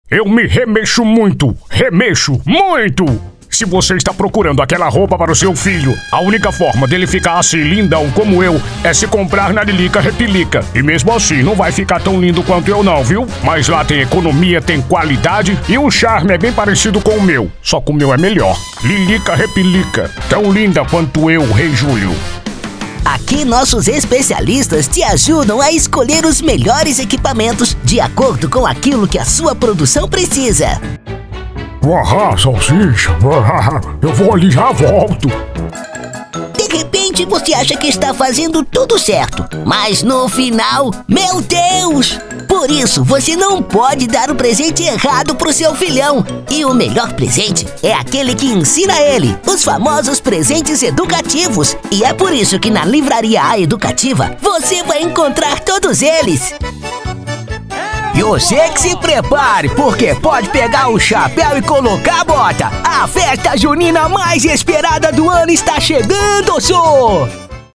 Caricatas:
Impacto
Animada